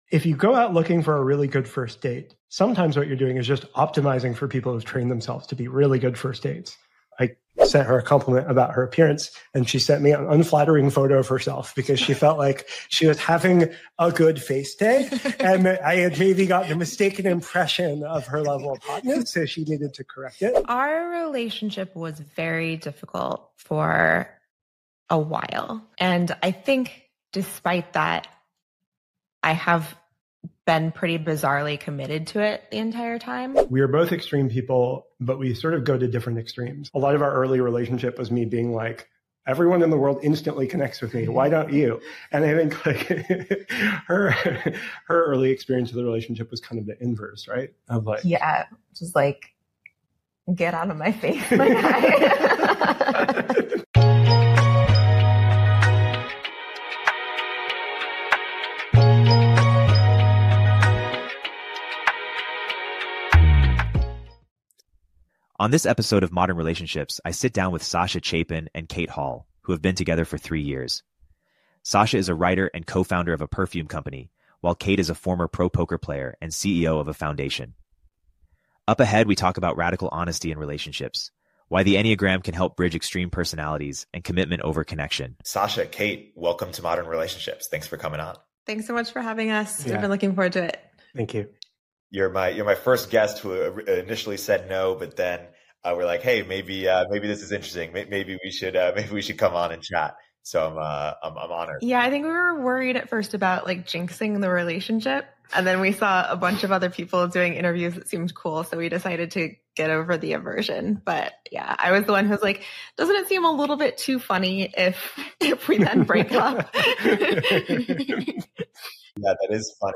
They discuss the concept of radical honesty in relationships and how the Enneagram can illuminate personality differences. Their conversation humorously navigates their contrasting backgrounds, exploring commitment versus connection.